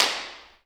OAK_clap_mpc_04.wav